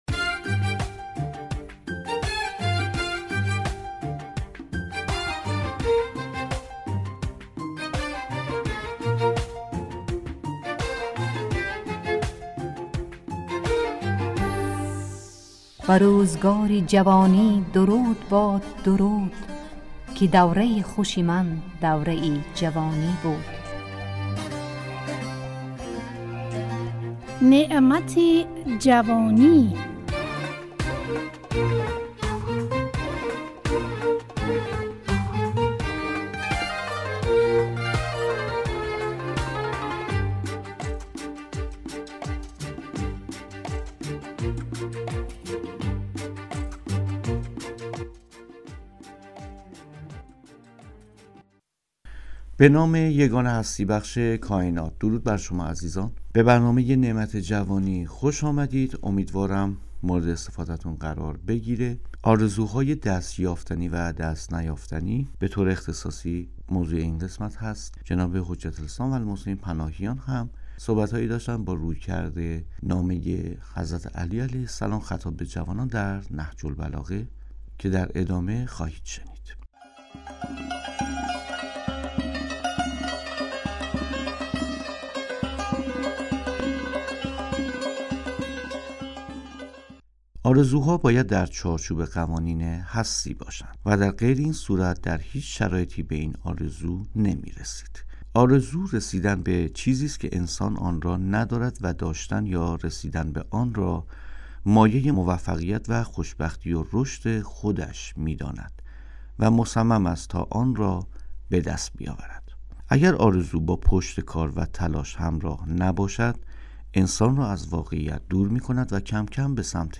نعمت جوانی، برنامه ای از گروه اجتماعی رادیو تاجیکی صدای خراسان است که در آن، اهمیت این دوران باشکوه در زندگی انسان مورد بررسی قرار می گیرد.